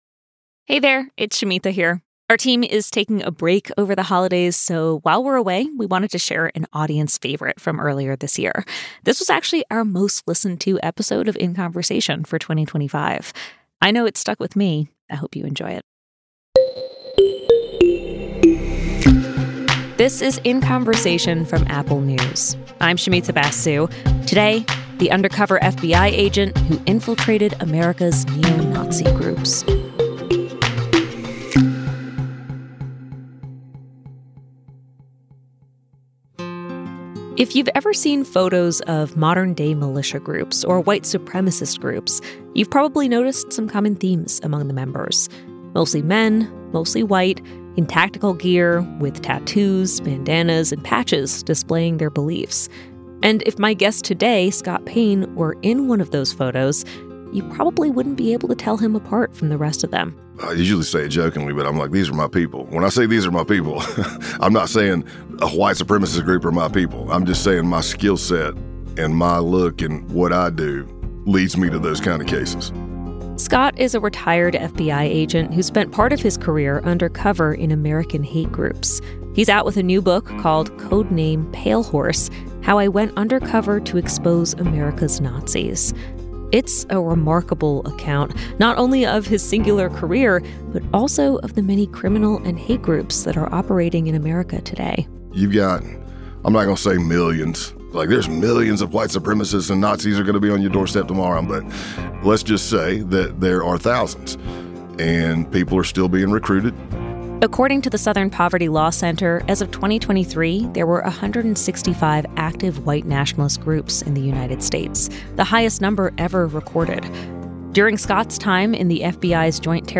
Here’s what he learned. 27:43 Play Pause 2h ago 27:43 Play Pause Play later Play later Lists Like Liked 27:43 This episode from our archives is our most-listened-to interview of the year.